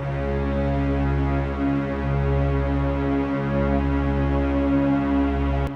Orchestra
cs4.wav